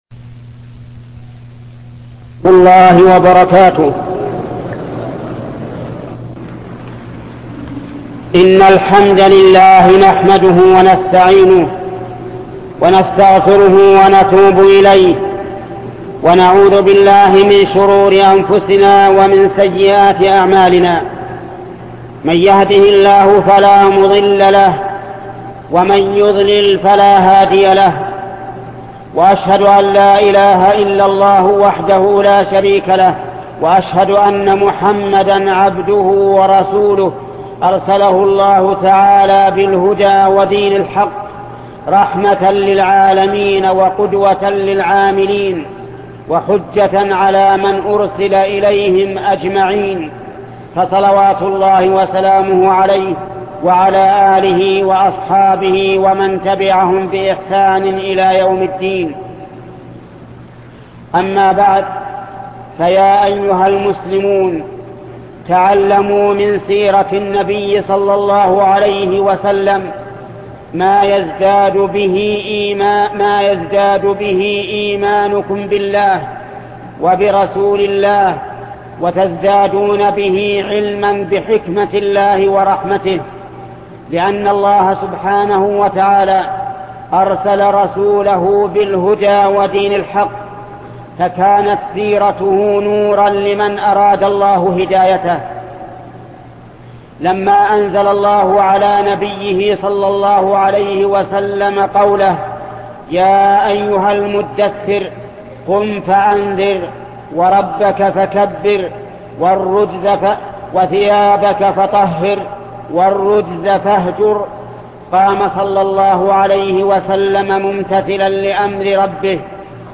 خطبة تنبيهات حول رحلة سعيدة الشيخ محمد بن صالح العثيمين